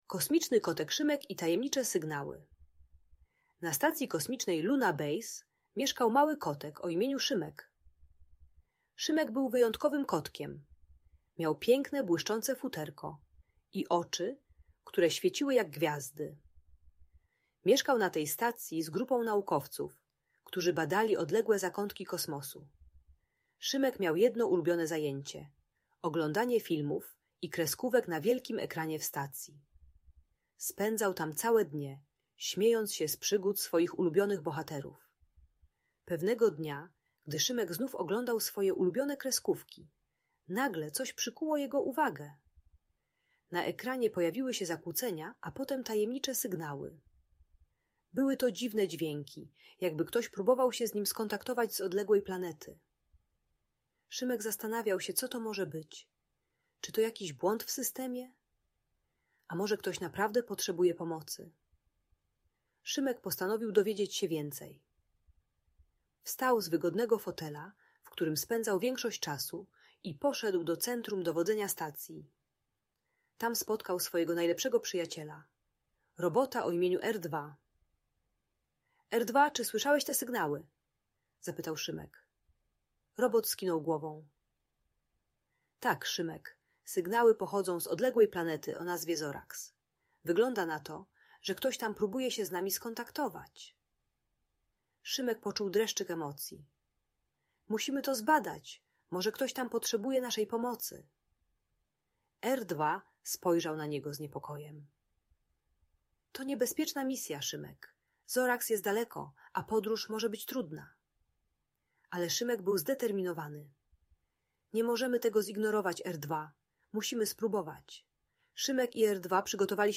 Kosmiczny Kotek Szymek - Audiobajka